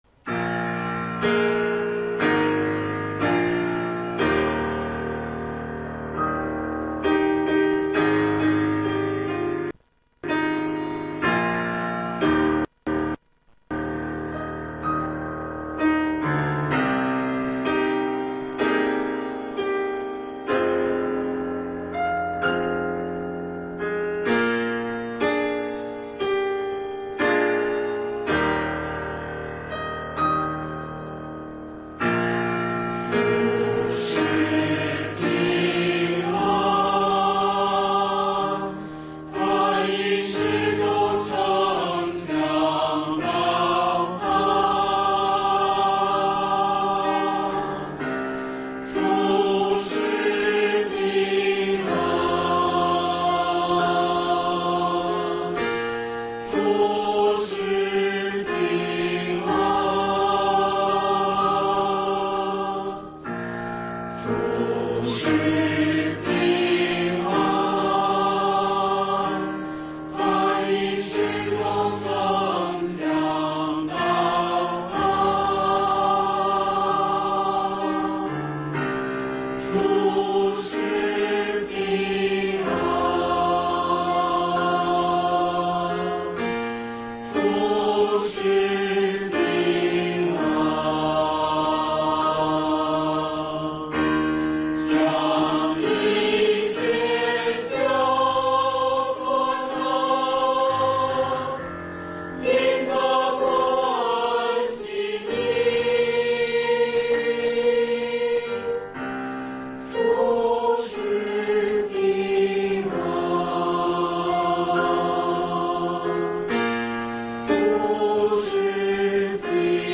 势不可挡的国度》 | 北京基督教会海淀堂